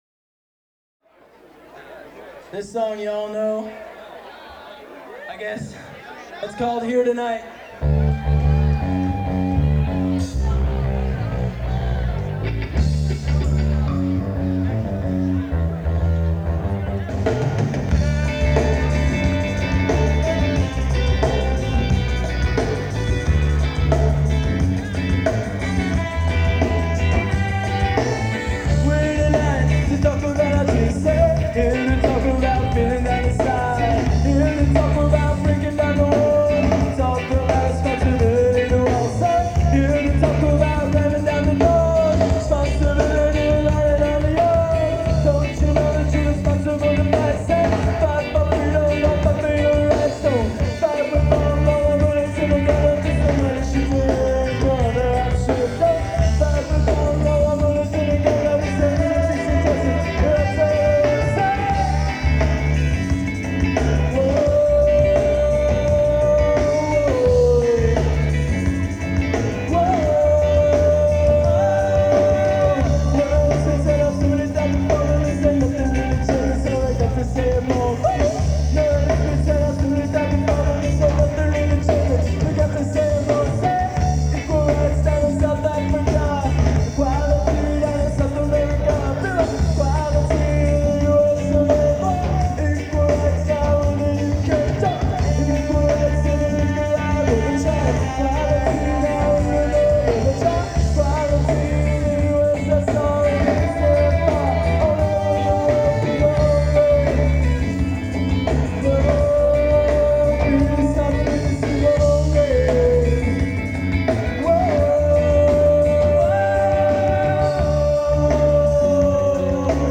Live Tapes